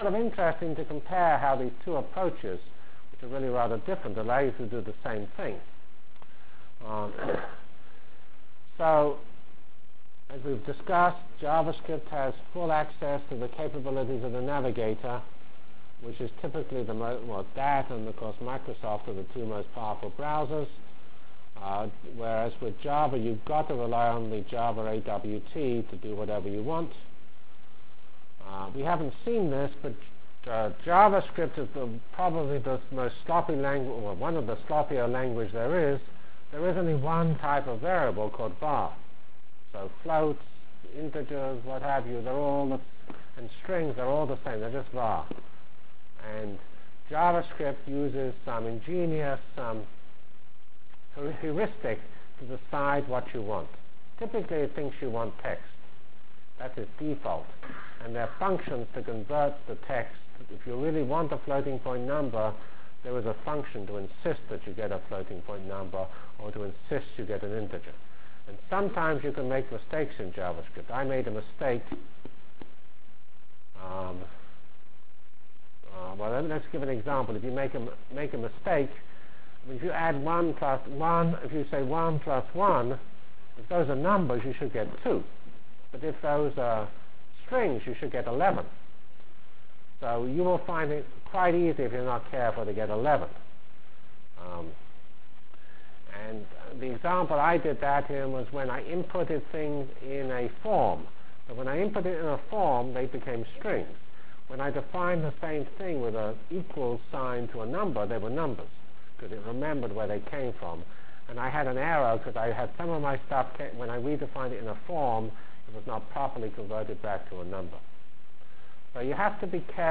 From Feb 12 Delivered Lecture for Course CPS616 -- Basic JavaScript Functionalities and Examples CPS616 spring 1997 -- Feb 12 1997.